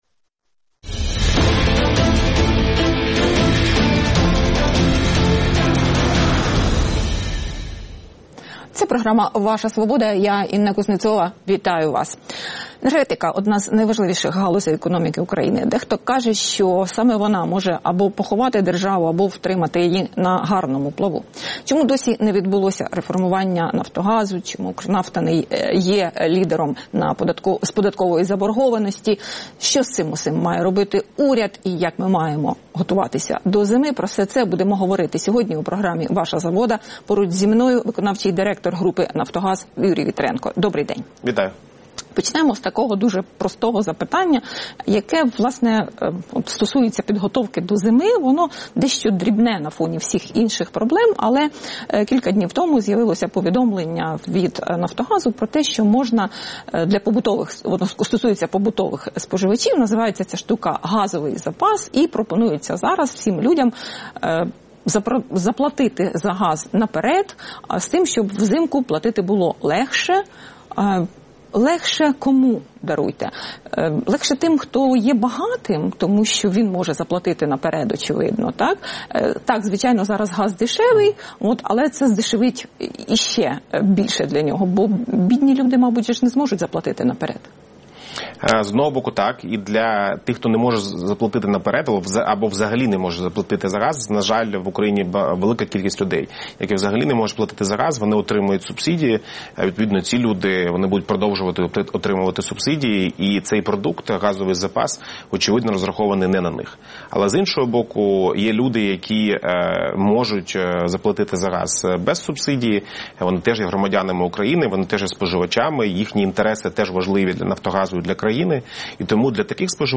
Про це говоримо з гостем програми.